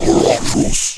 spawners_mobs_mummy_spell.2.ogg